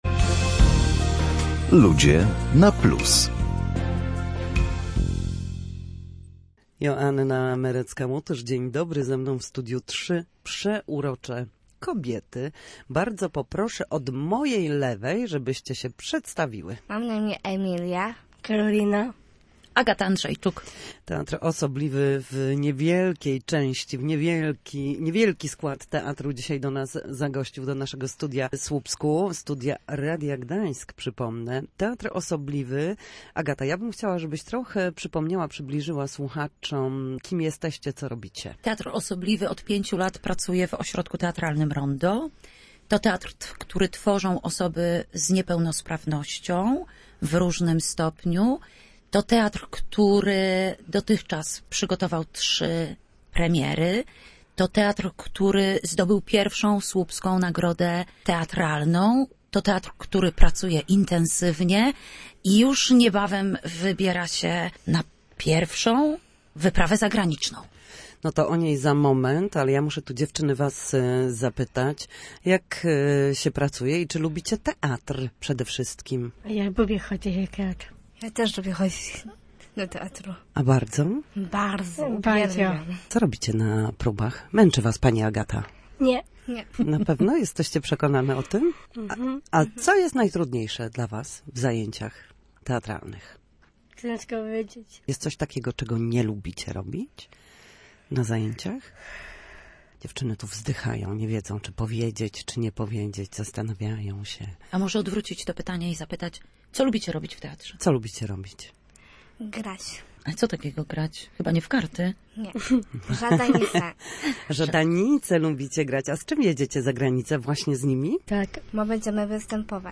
W audycji Ludzie na Plus gościliśmy dziś Teatr Osobliwy.